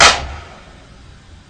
Clap (6).wav